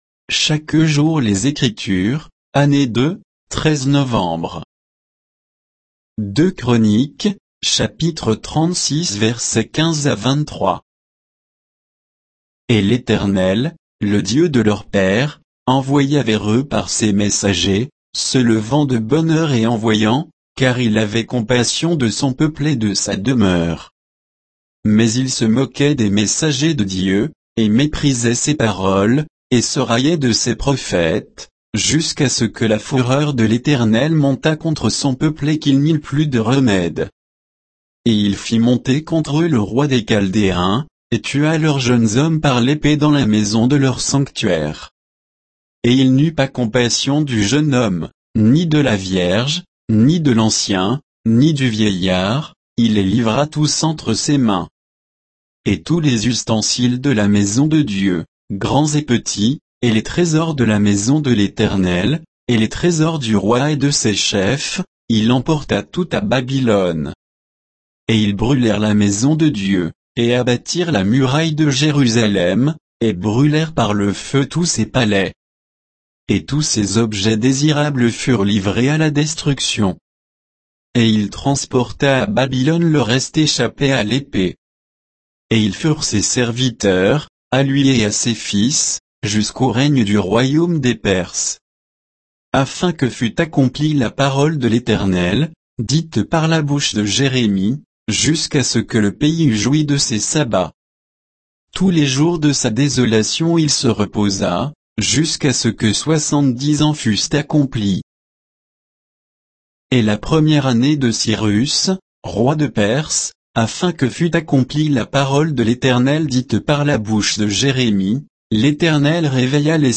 Méditation quoditienne de Chaque jour les Écritures sur 2 Chroniques 36